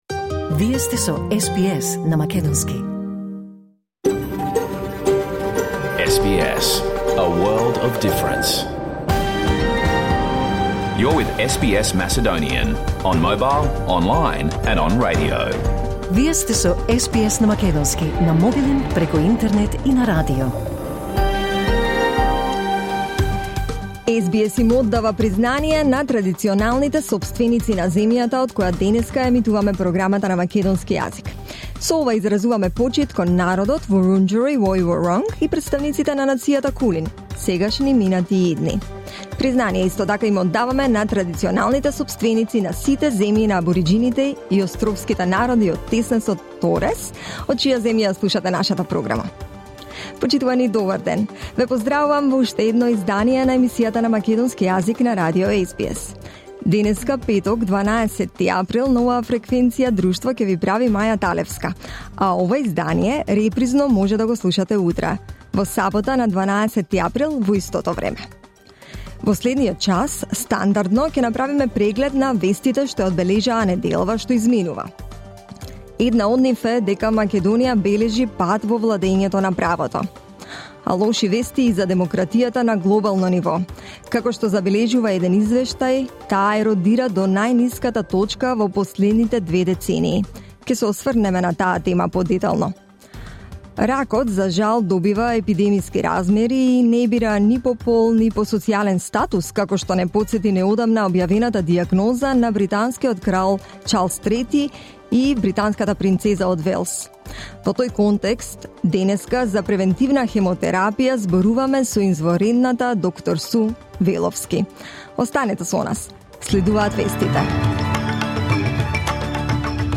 SBS Macedonian Program Live on Air 12 April 2024